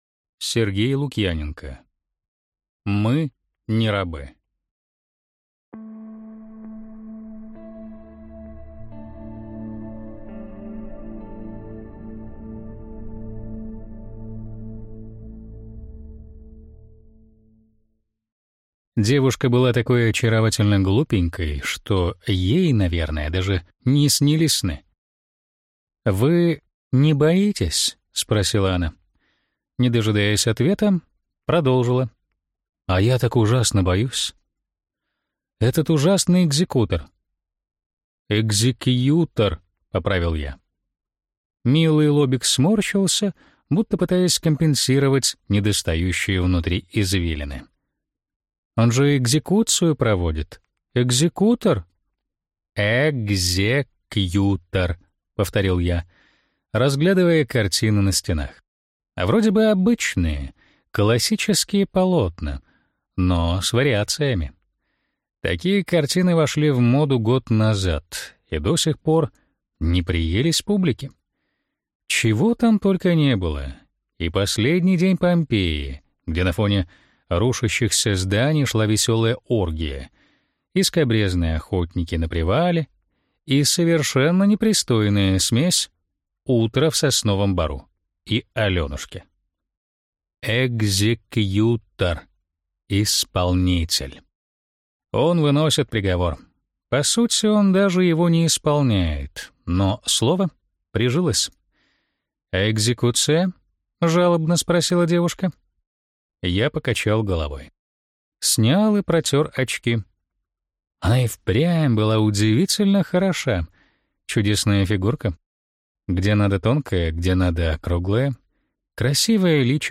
Аудиокнига Мы не рабы. Человек, который многого не умел | Библиотека аудиокниг